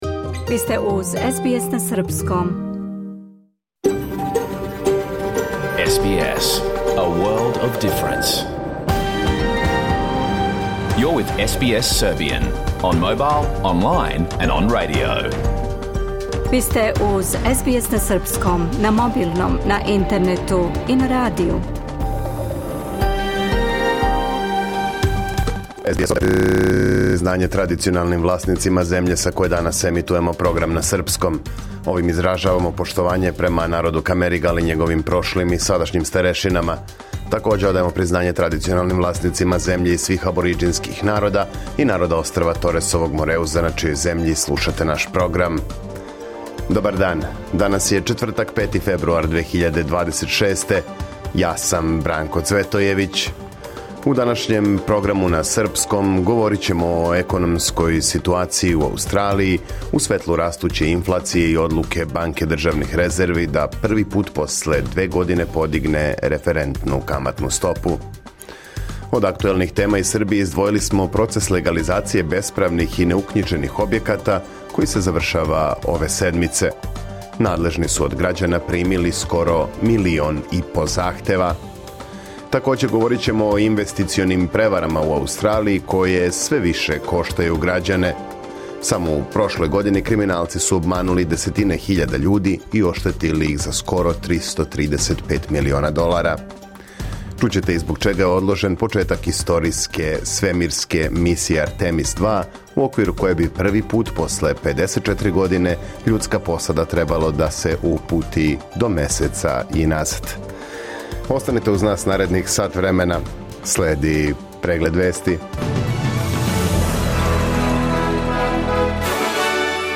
Програм емитован уживо 5. фебруара 2026. године